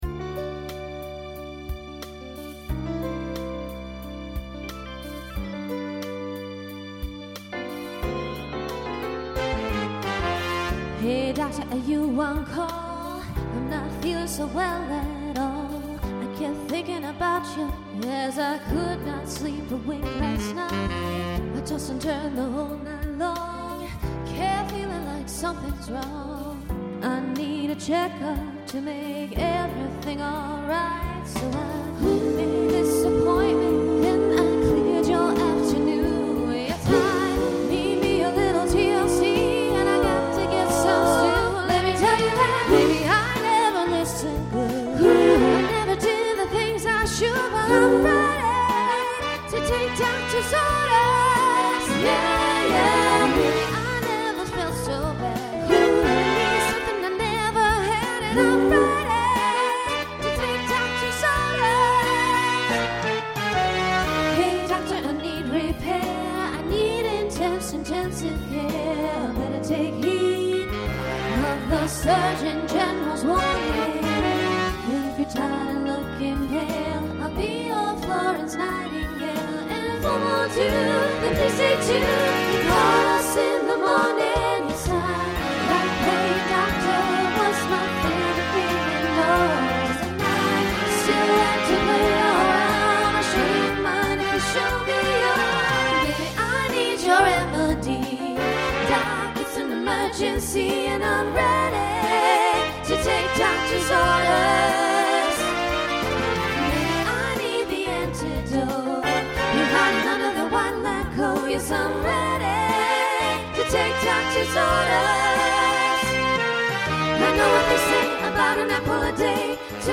New SAB voicing for 2025.